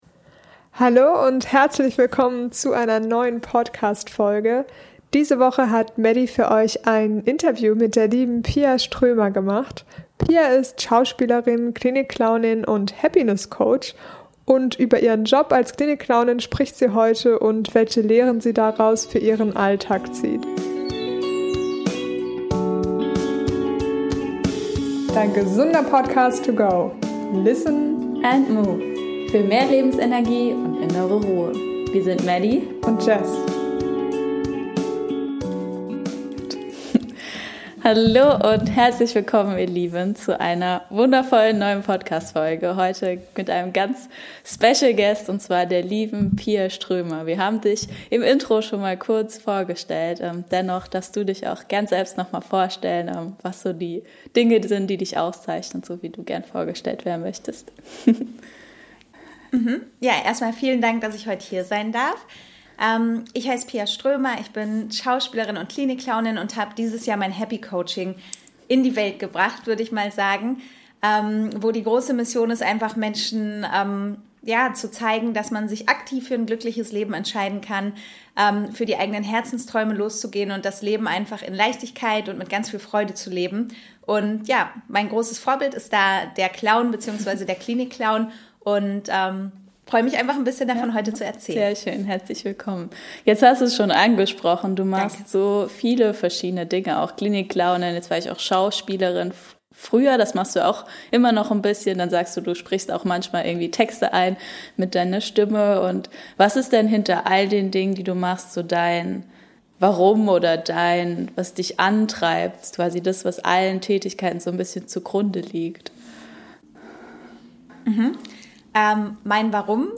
In der aktuellen Podcastfolge interviewen wir die lebensfrohe und bezaubernde